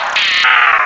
cry_not_drapion.aif